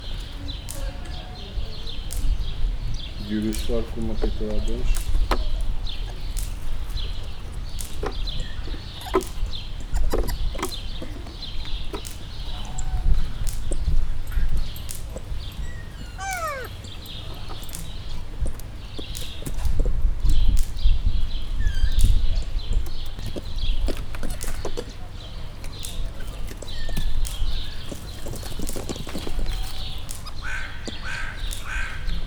debrecenizoo2019_professzionalis/gyurusfarku_maki